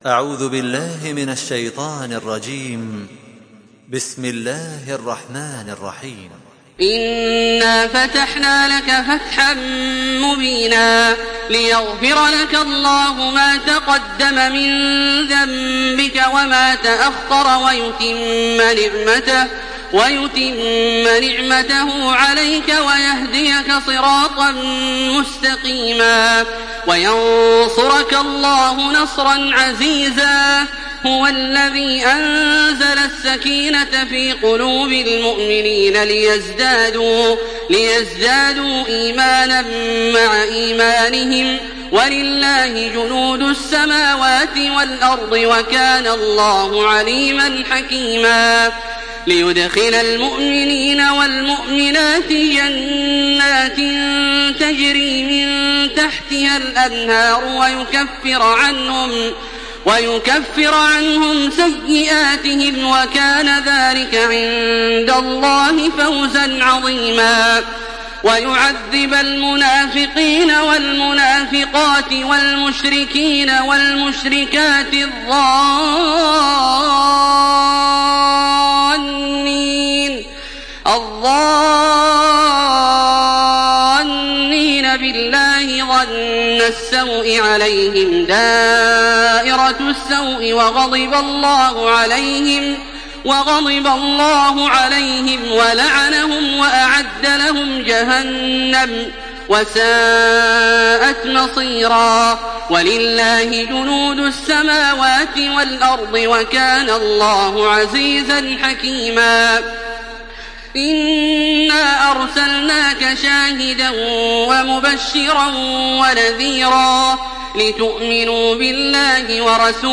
Surah Fetih MP3 by Makkah Taraweeh 1427 in Hafs An Asim narration.
Murattal